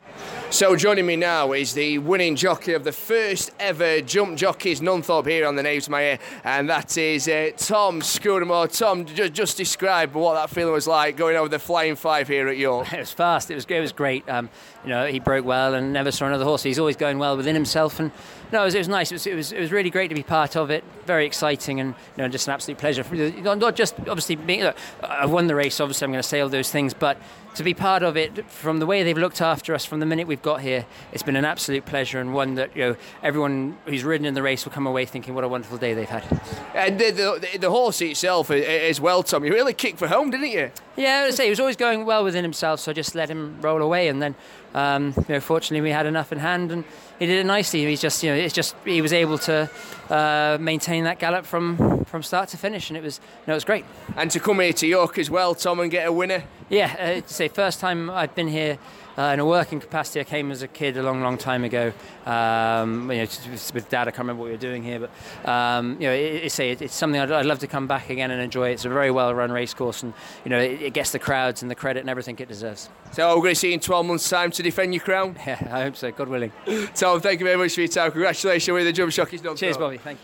Top jump jockey Tom Scudamore speaks